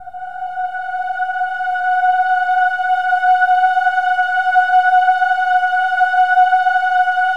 OH-AH  F#5-L.wav